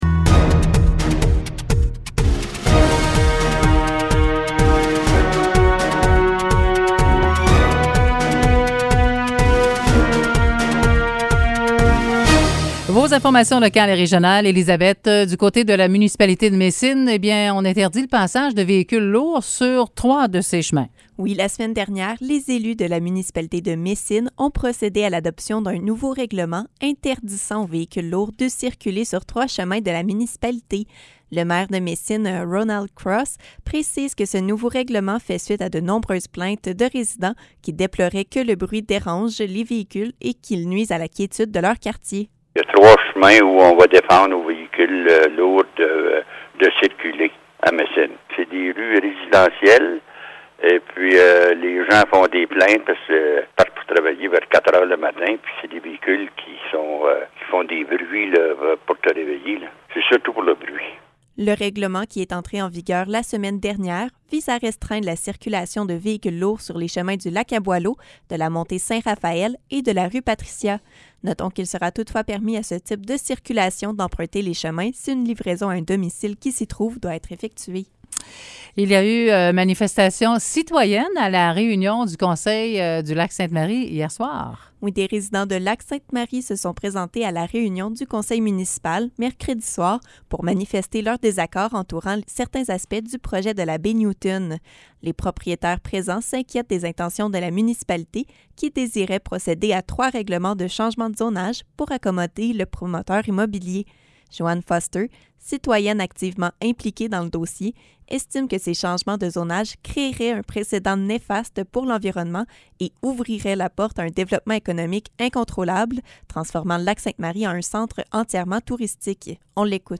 Nouvelles locales - 15 juillet 2021 - 10 h